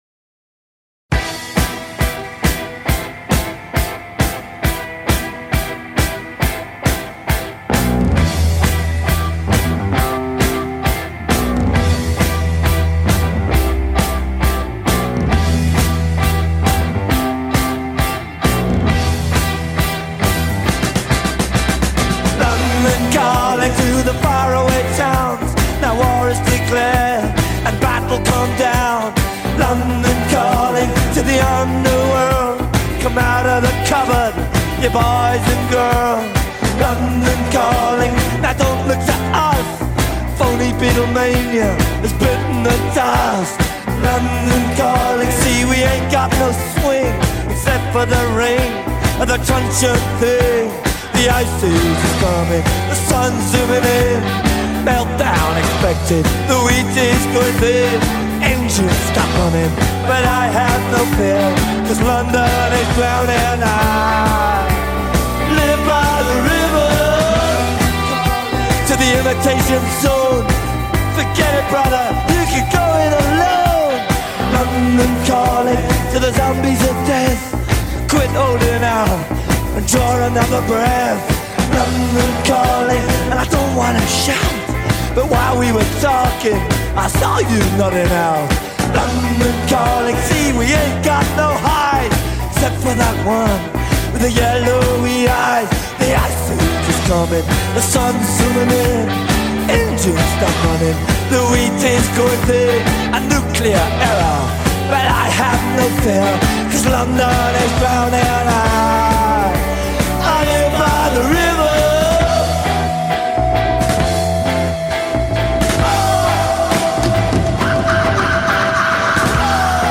Punk Rock, Post-Punk